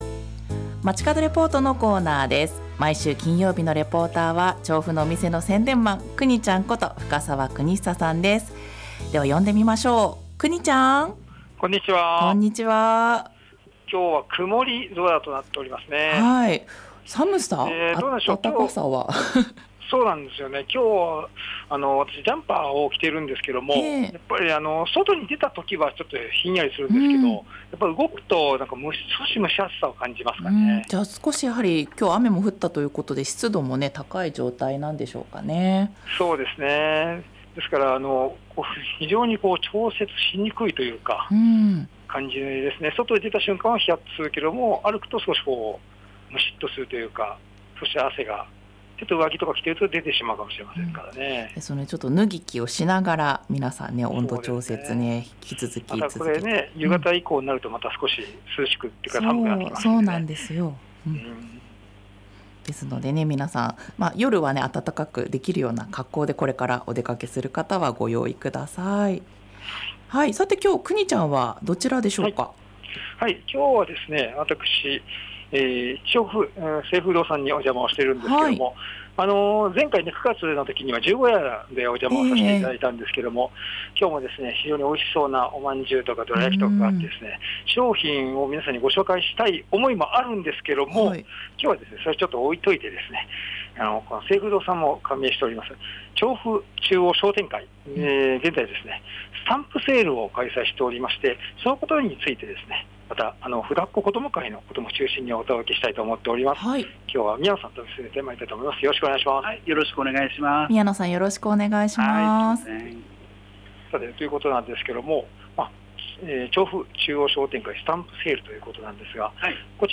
さて今週は調布中央商店会さんにお邪魔しました。